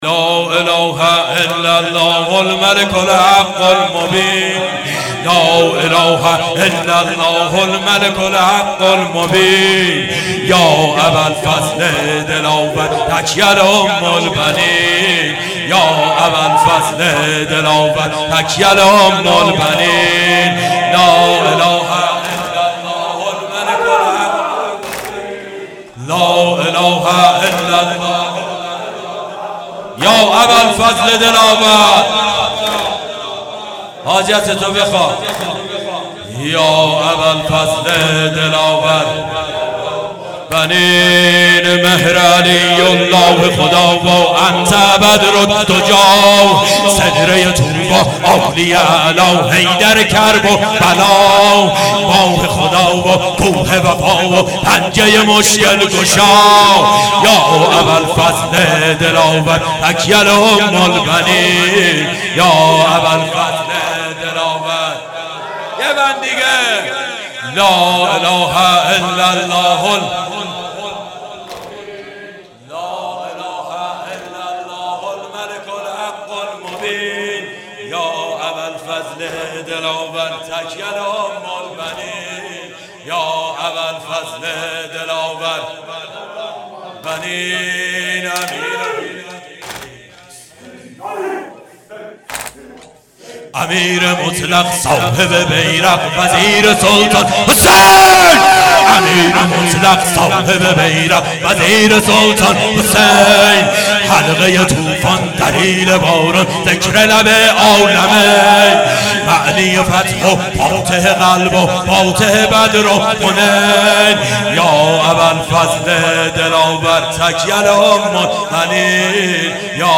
مکان:شهرک شهدا نبش پارک لاله هیئت علمدار سنقر